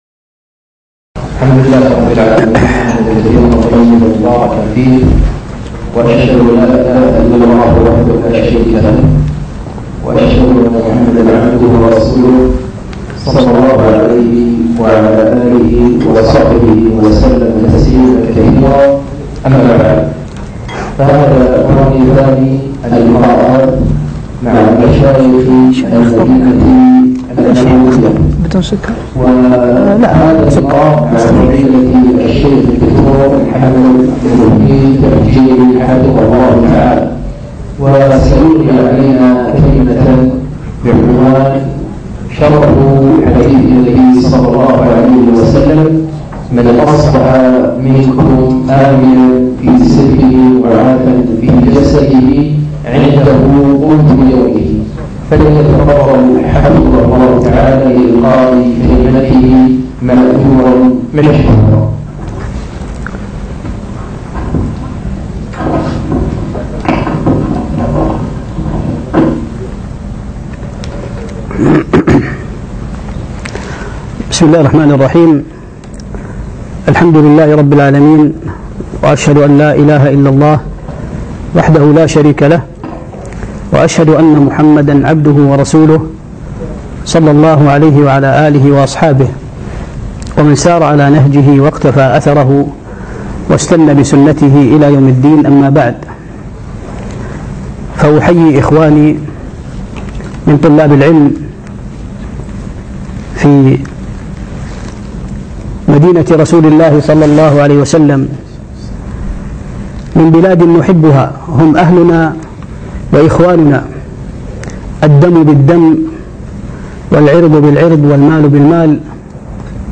شرح حديث